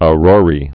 (ə-rôrē)